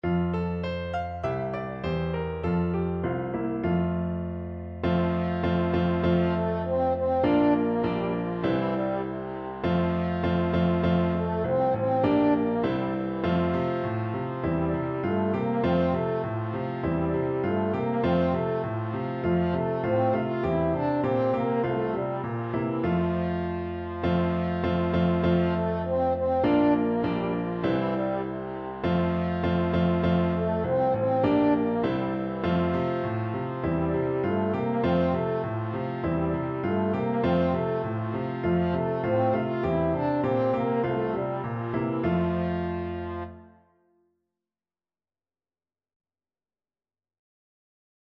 French Horn
2/2 (View more 2/2 Music)
C4-F5
Two in a bar =c.100
F major (Sounding Pitch) C major (French Horn in F) (View more F major Music for French Horn )